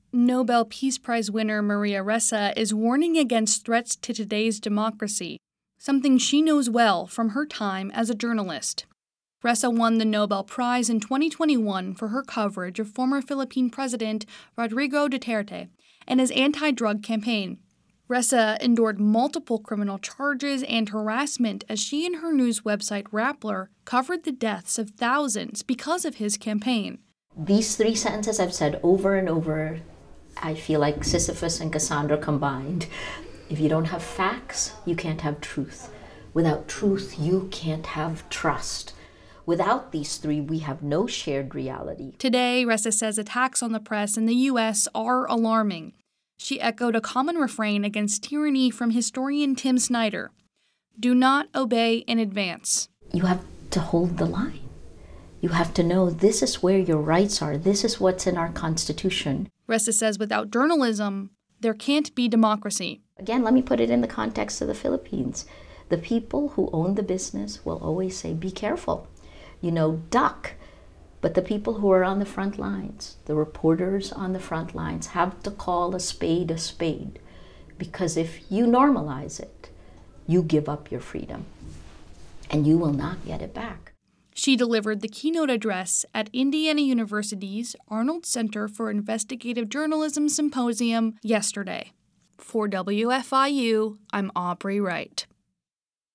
In a Q&A with WFIU/WTIU News, Ressa discussed journalism and the state of democracy today. This conversation was edited for length and clarity.